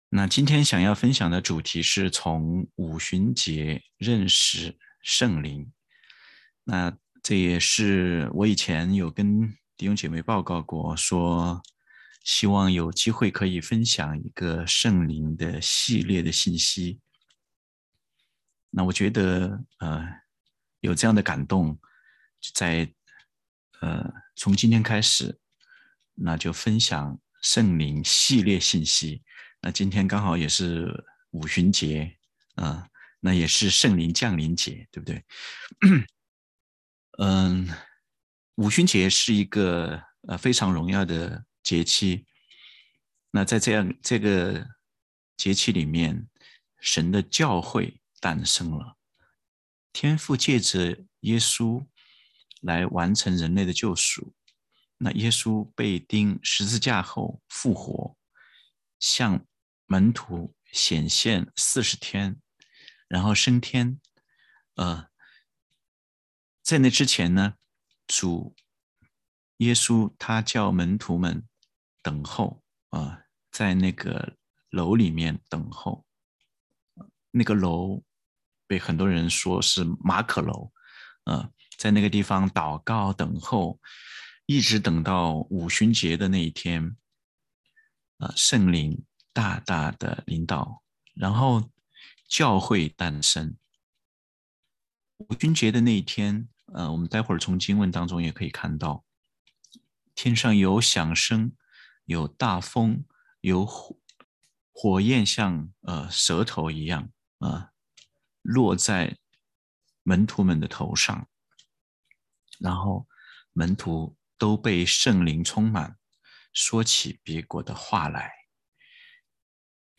讲道 – 第 42 页 – 荣美基督教会